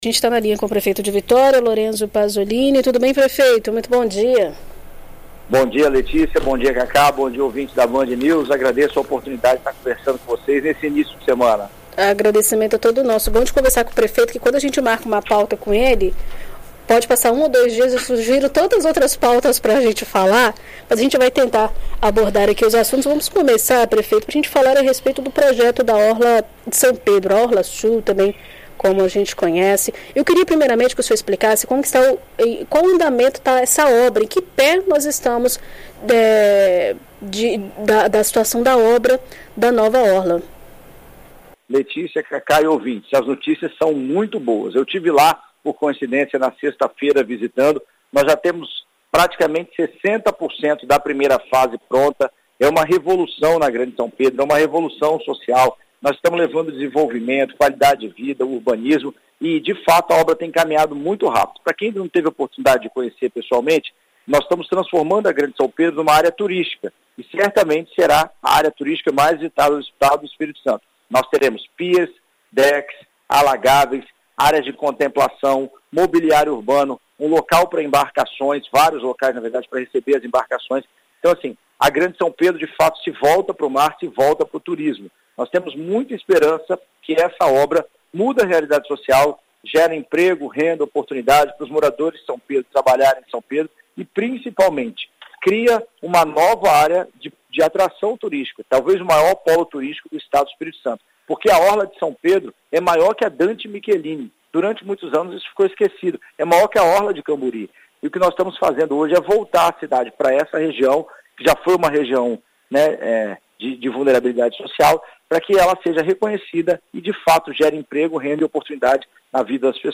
Em entrevista a BandNews FM Espírito Santo o prefeito de Vitória, Lorenzo Pazolini, dá detalhes sobre os trabalhos realizados no local